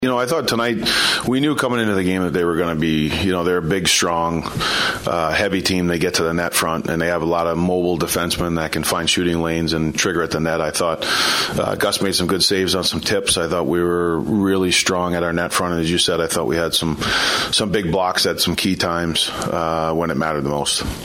Wild Head Coach John Hynes recaps the win.